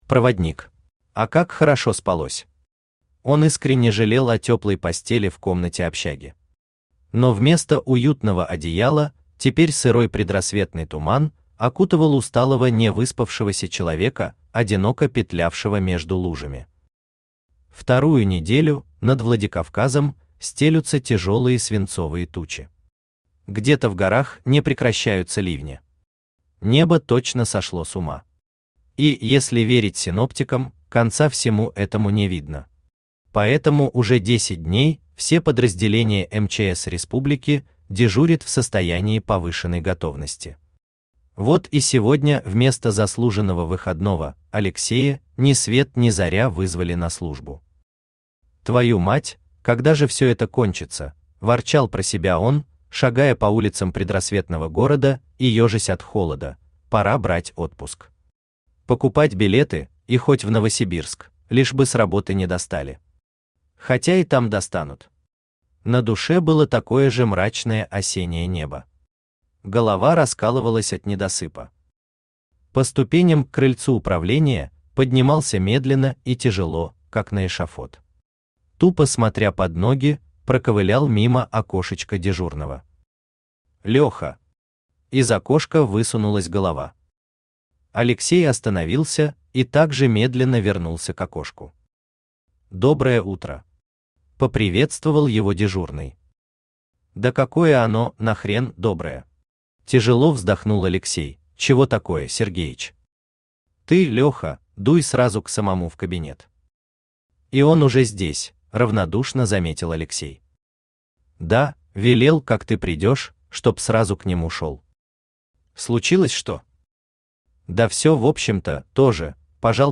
Сборник рассказов Автор Артем Лобчук Читает аудиокнигу Авточтец ЛитРес.